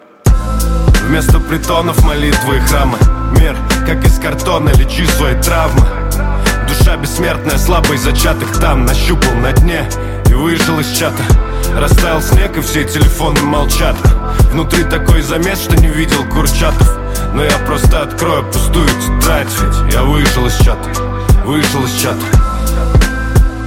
Музыка » Rap/Hip-Hop/R`n`B » Хип-хоп викторина